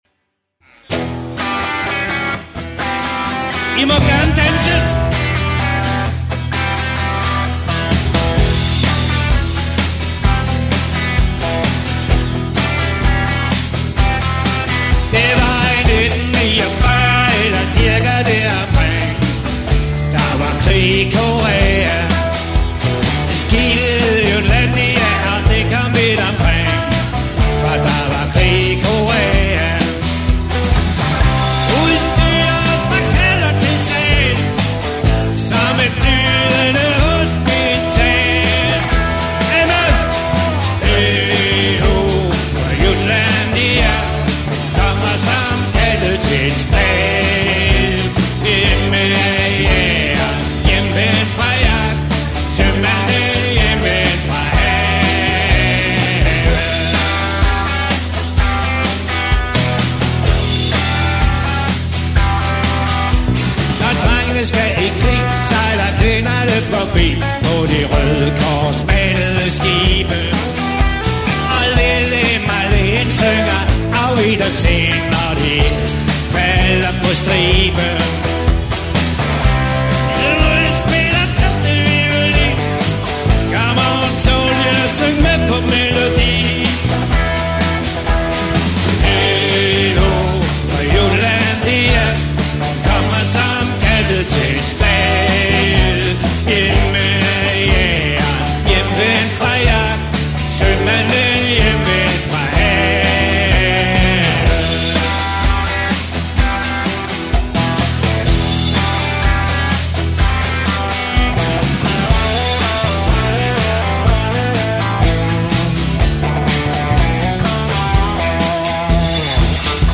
Danmarks hyggeligste partyband
• Allround Partyband
• Coverband